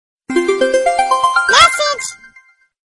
Darmowe dzwonki - kategoria SMS
Dziecięcy głos w stylu newsa z hasłem 'message'.